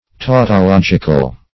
Tautological \Tau`to*log"ic*al\, a. [Cf. F. tautologique.]